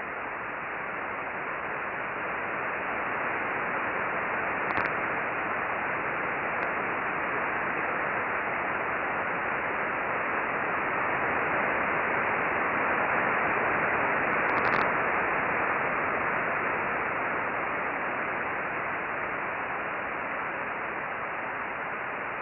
The chart above shows a 10 minute time span with a solar burst covering almost the full span. There was some periodic RFI at 20 MHz but it was overwhelmed by the solar burst.